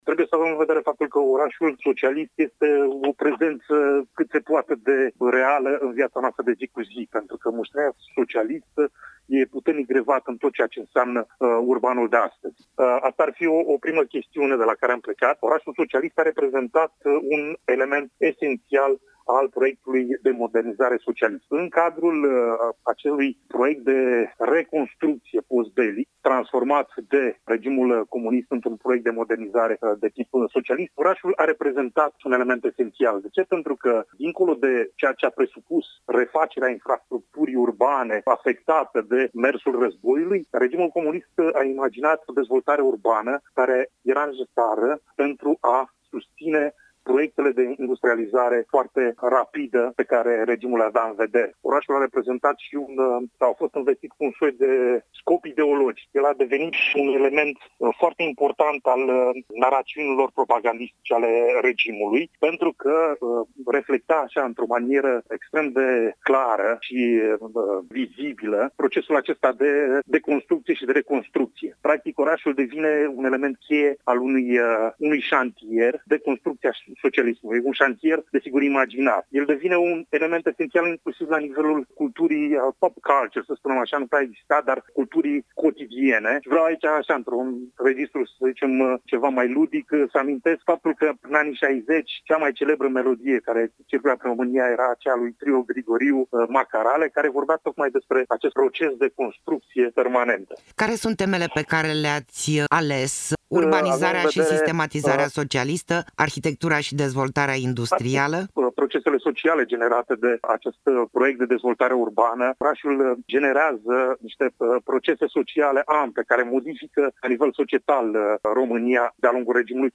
Interviu-orase-socialiste.mp3